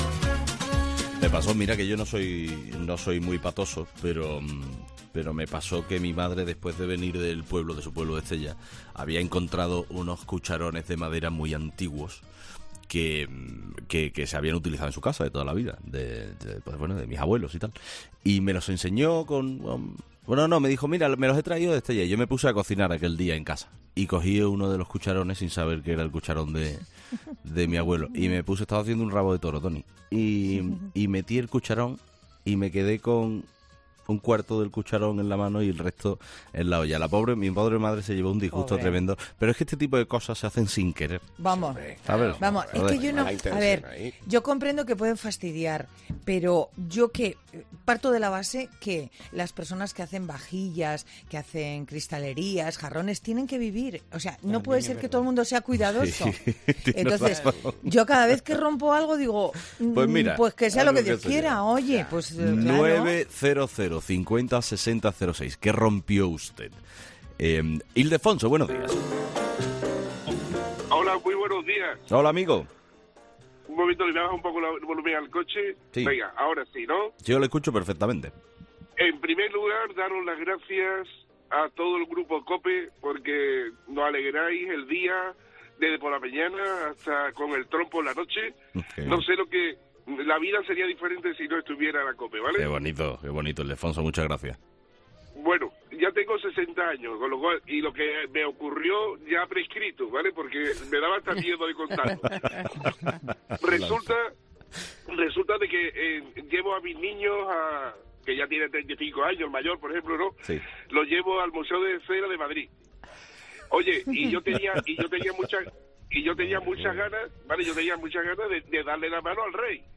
AUDIO: Los oyentes de Carlos Herrera nos cuentan las historias en las que no sabían dónde meterse tras lo que les sucedió